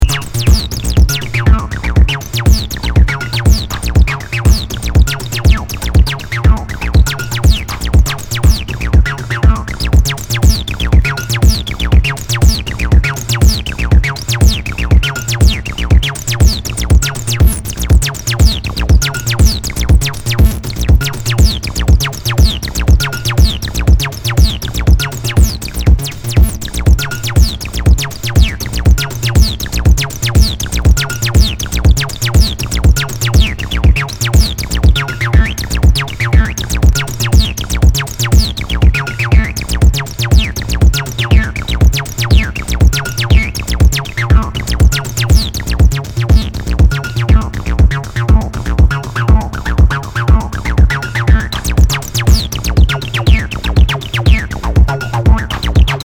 HOUSE/TECHNO/ELECTRO
ナイス！アシッド・ハウス・クラシック！
残念ですが大きく盤に歪みあり。辛うじて再生(ピッチは狂うかもしれません）は出来ましたが両面イントロ部は音飛びします。
類別 House